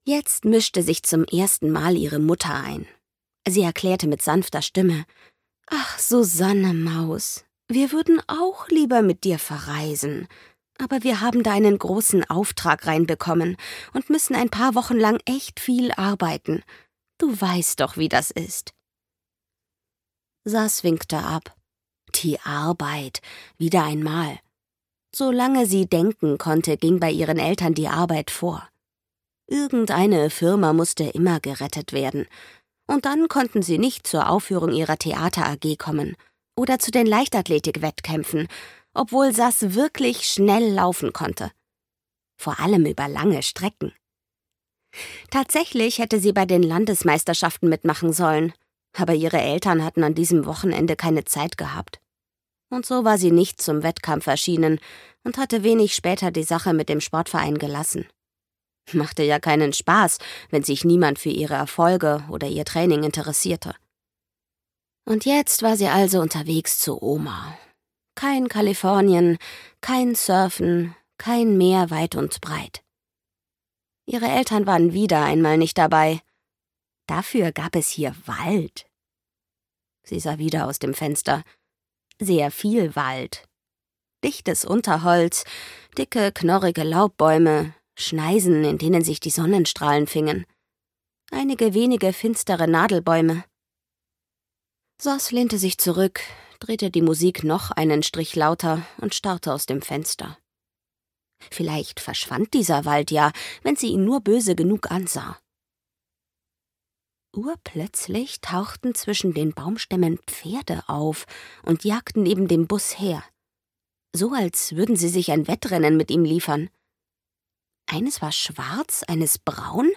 Wilde Horde 1: Die Pferde im Wald - Katrin Tempel - Hörbuch